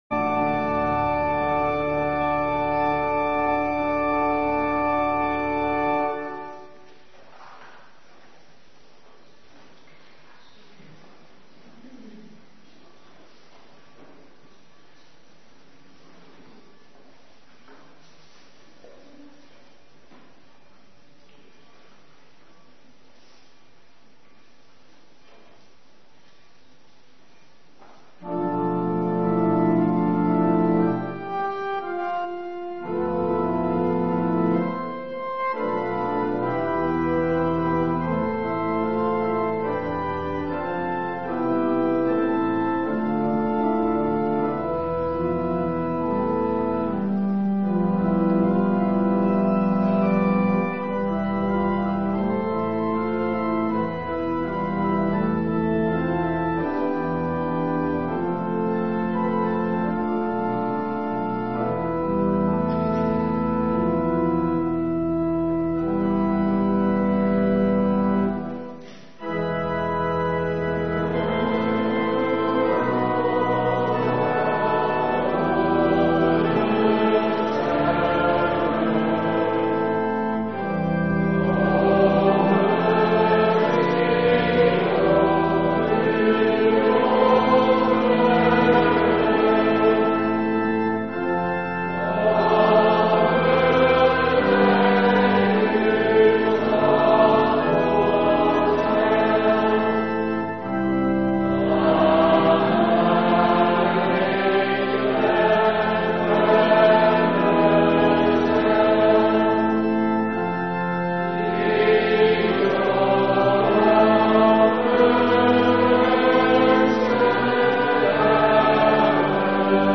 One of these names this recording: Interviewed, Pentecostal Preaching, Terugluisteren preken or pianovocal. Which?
Terugluisteren preken